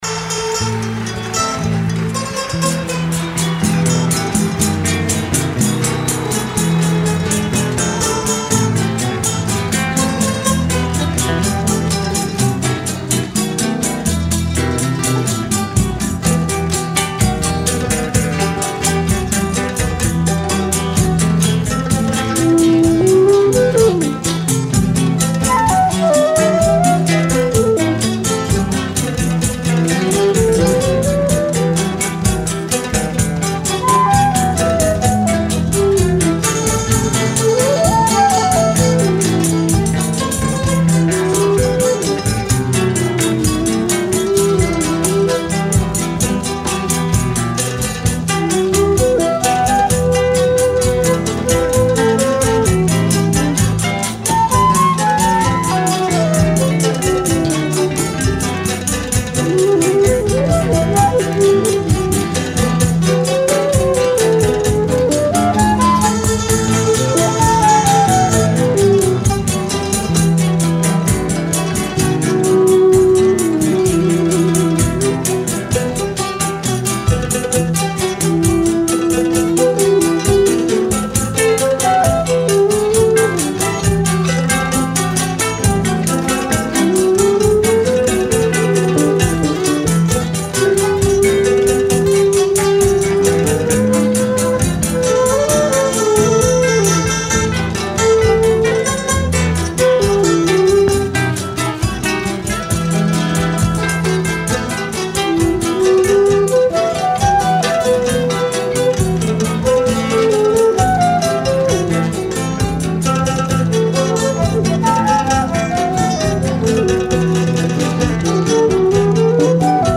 1136   02:30:00   Faixa:     Samba Canção
Bandolim